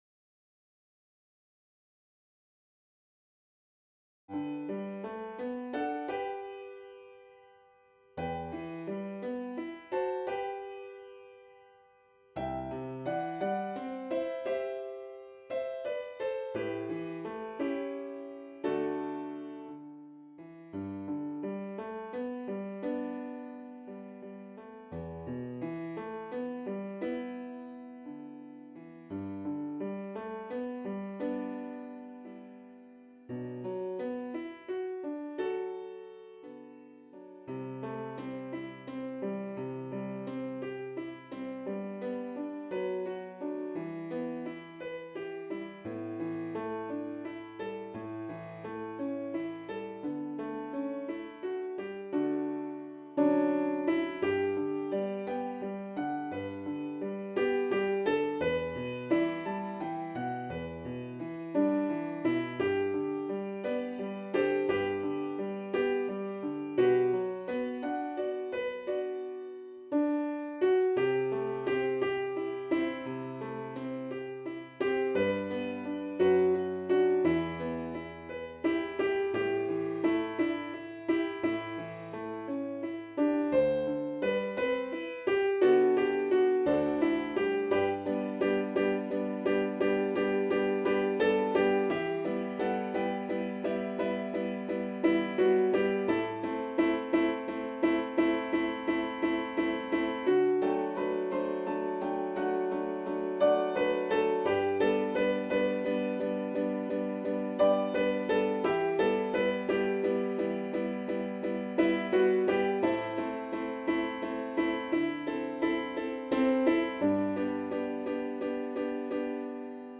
Turn_Around_Again_Alto_v2.mp3